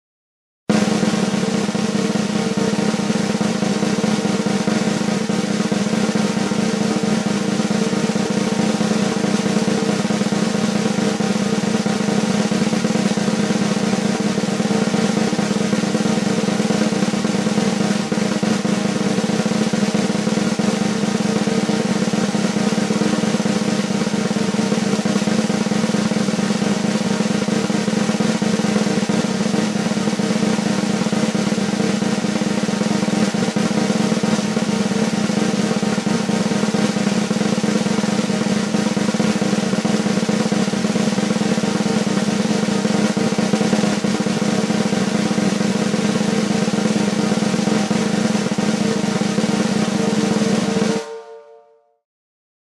جلوه های صوتی
دانلود صدای تبل 1 از ساعد نیوز با لینک مستقیم و کیفیت بالا